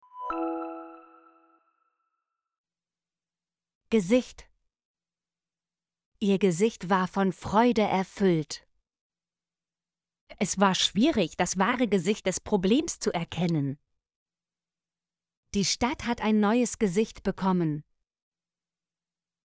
gesicht - Example Sentences & Pronunciation, German Frequency List